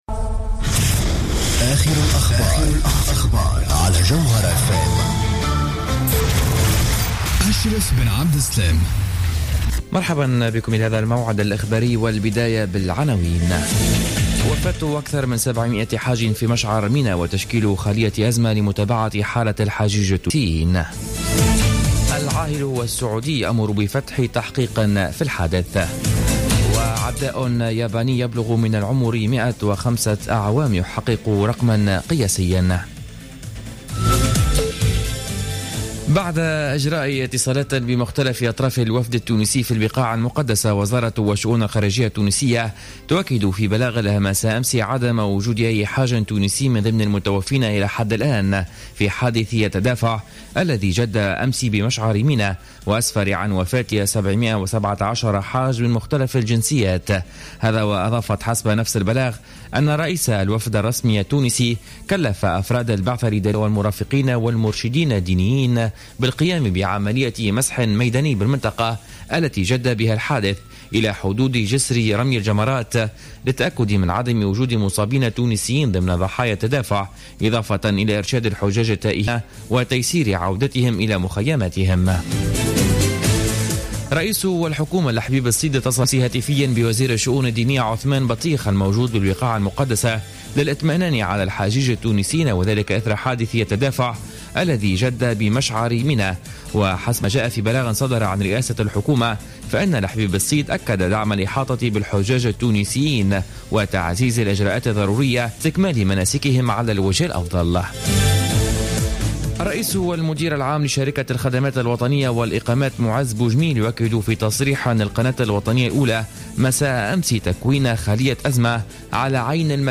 نشرة أخبار منتصف الليل ليوم الجمعة 25 سبتمبر 2015